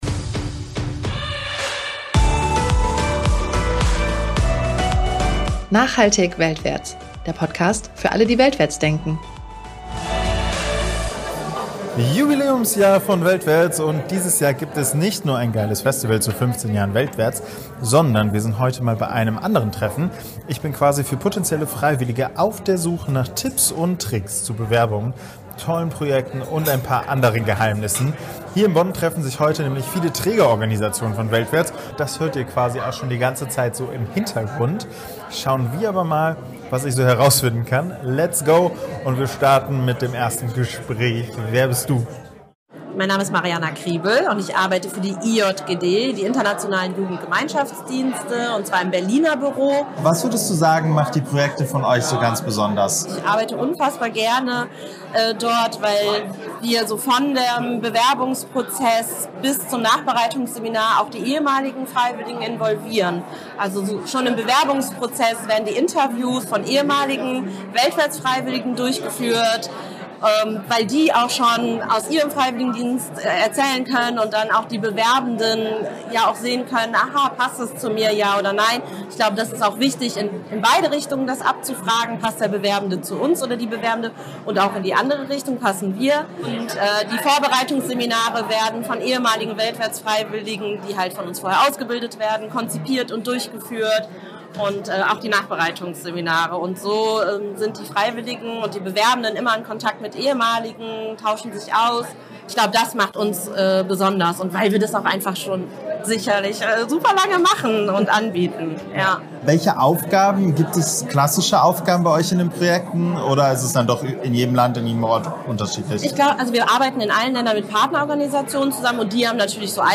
Wir haben Mitarbeitende von verschiedenen Organisationen gefragt.